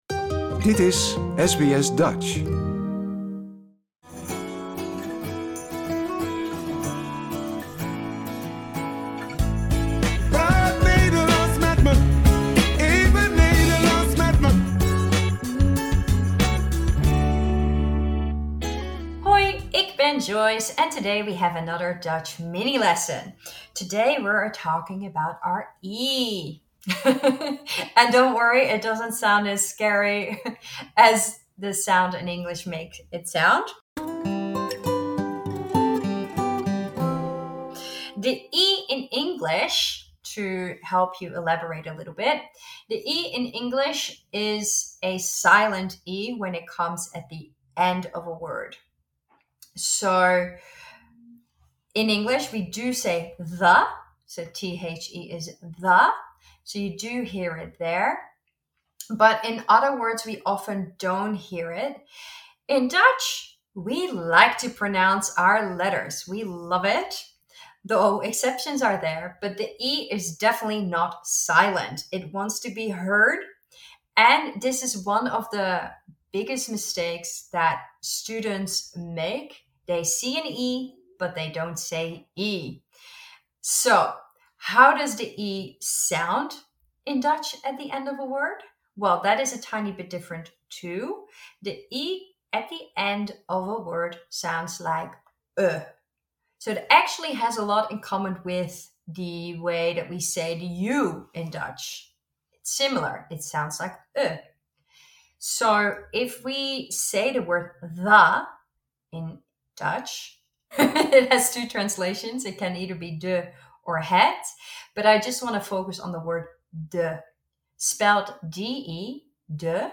Words from lesson 6: the E at the end of an adjective In English, the letter E at the end of the word is soft; in Dutch, you need to pronounce the E. In Dutch, the E at the end of a word, sounds like 'uhh' (like you're thinking).